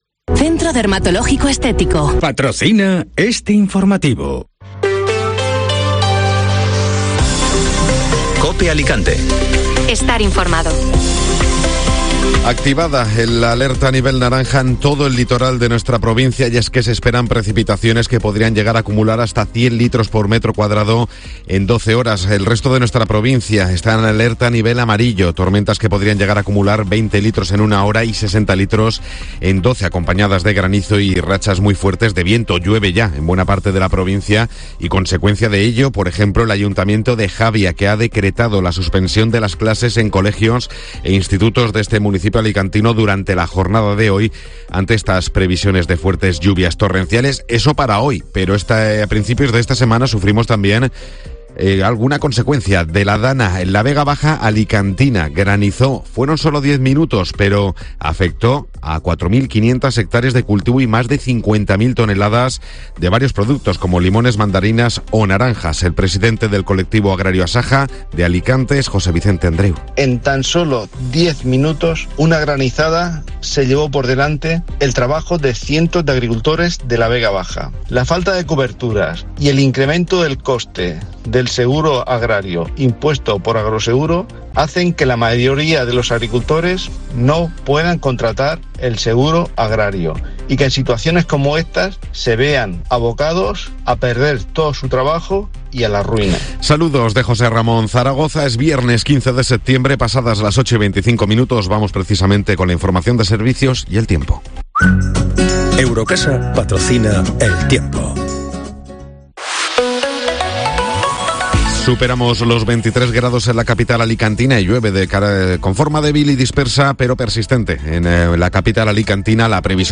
Informativo Matinal (Viernes 15 de Septiembre)